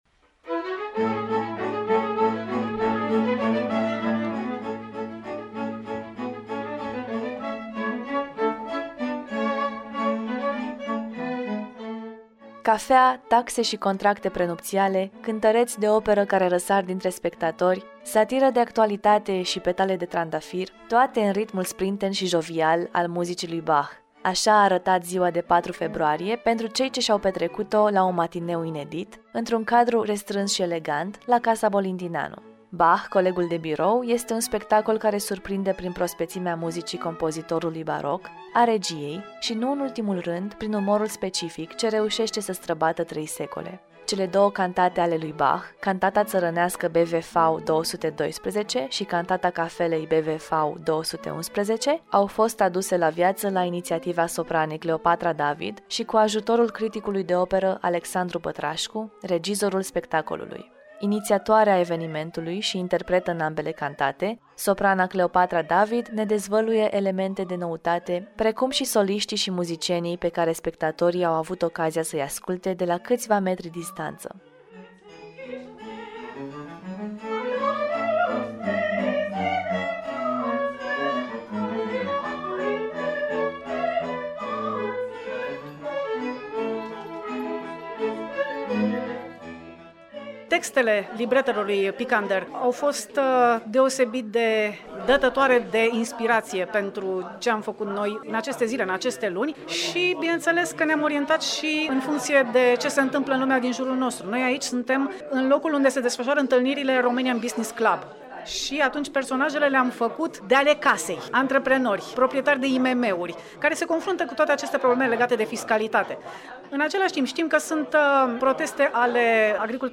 Interviul de la Radio Trinitas